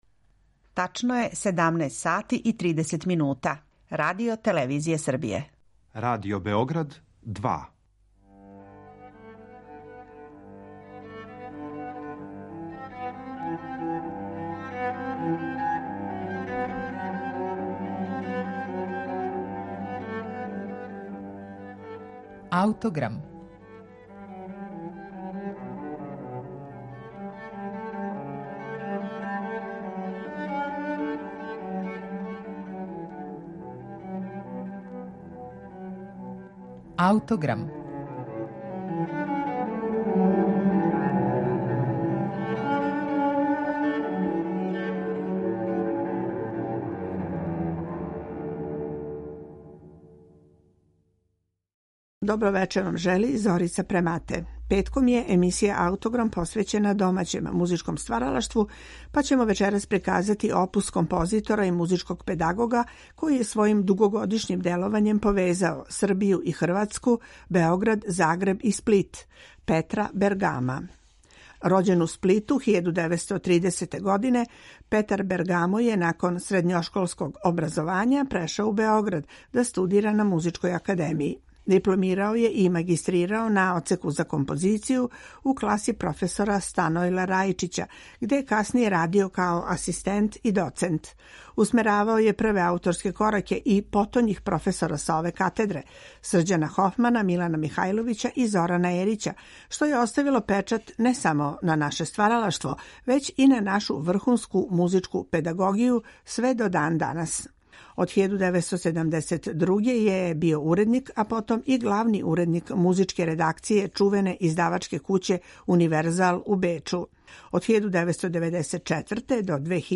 Уз инструментални ансамбл